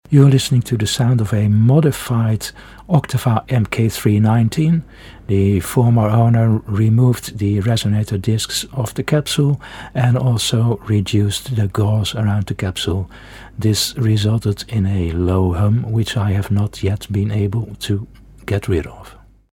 Below: Sound of MK 219 and MK 319, MK319 & info
MK 319 (with low cut)
Oktava MK319 sound UK with low cut.mp3